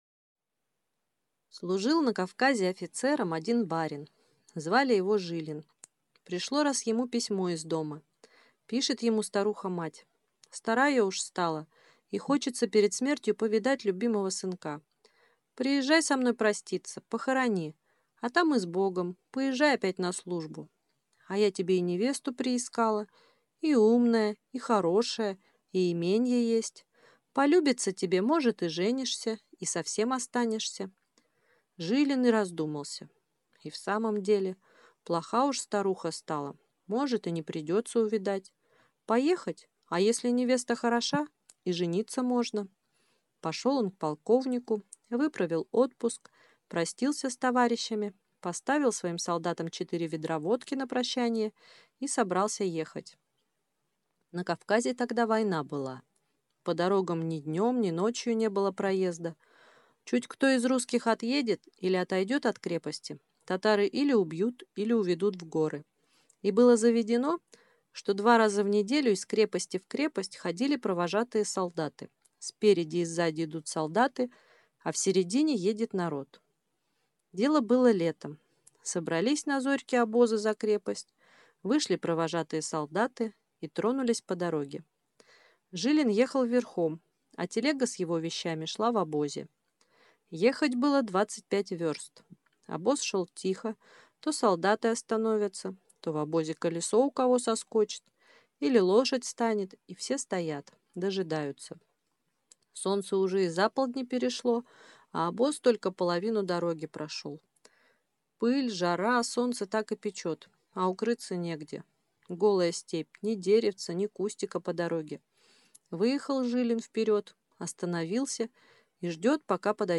Аудиокнига Кавказский пленник - купить, скачать и слушать онлайн | КнигоПоиск